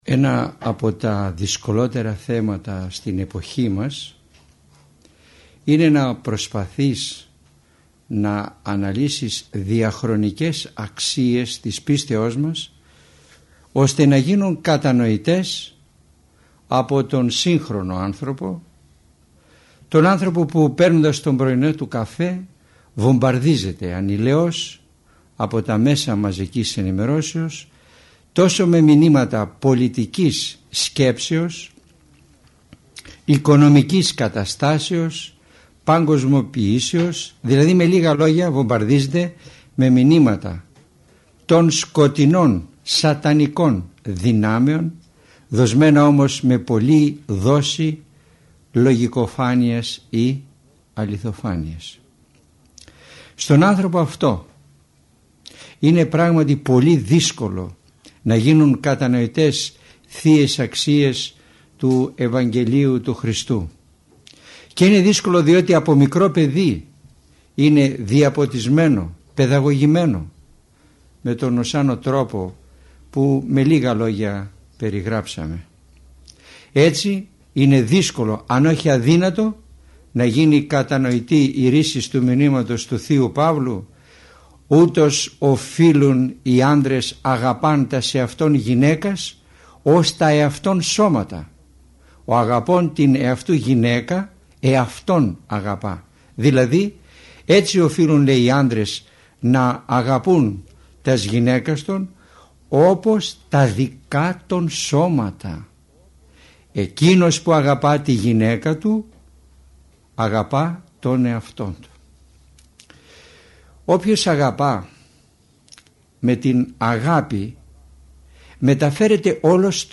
Πρόκειται για παραγωγή της Ιεράς Μονής Κοιμήσεως της Θεοτόκου Κλειστών Αττικής.
Ελευθερία επιλογής Το μεγάλο πλεονέκτημα των ομιλιών Κάθε ομιλία είναι ένα ζωντανό κήρυγμα, όπου το παν εξαρτάται από τη θέληση του ακροατή˙ ο τόπος, ο χρόνος και ο τρόπος ακρόασης, το θέμα της ομιλίας εναπόκεινται στην προσωπική του επιλογή.